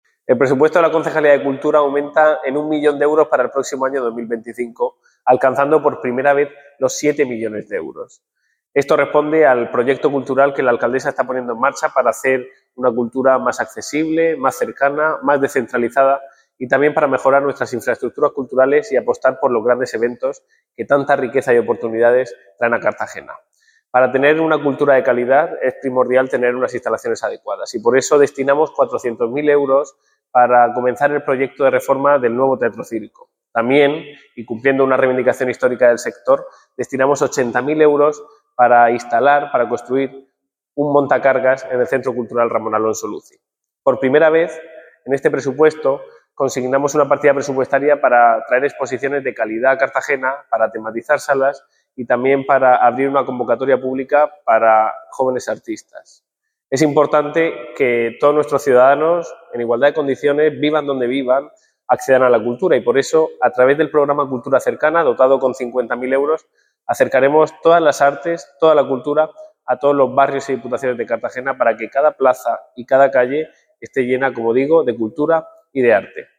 Enlace a Declaraciones de Ignacio Jáudenes sobre presupuesto de Cultura 2025